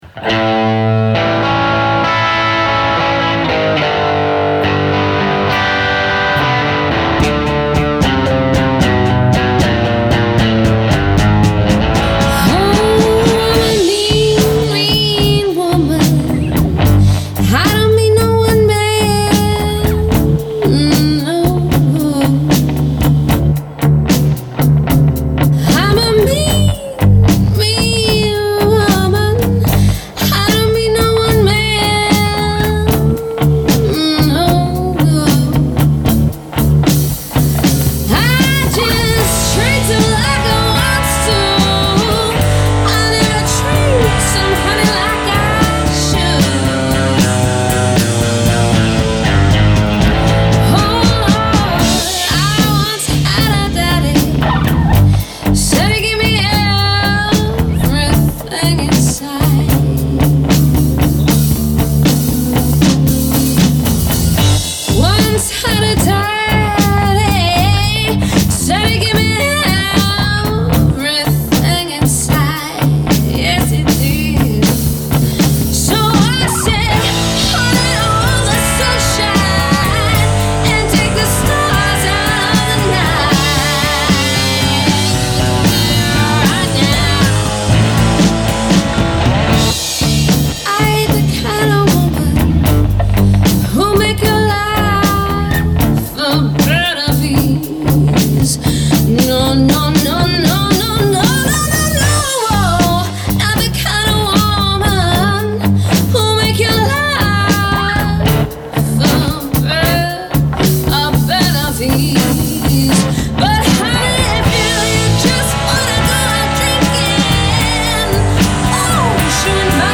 Creephouse Studio Audio Recordings
(wasted version)